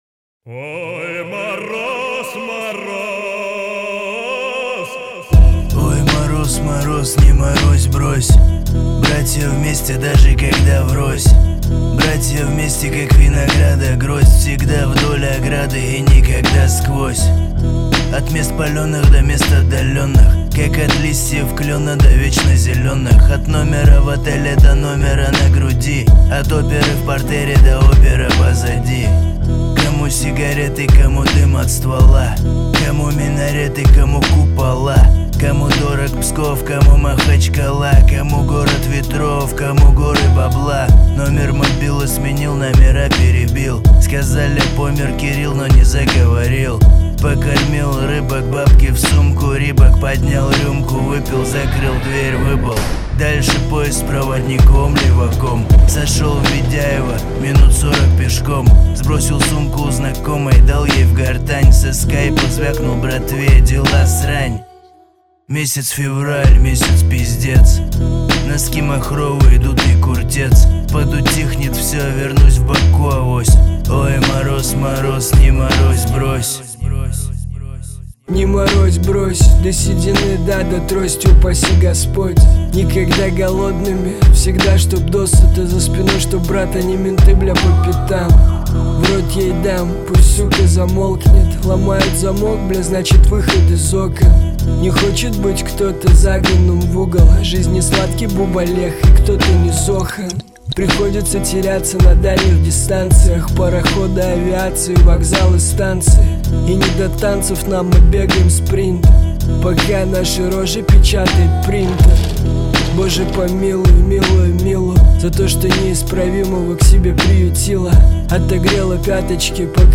Назад в (рэп)...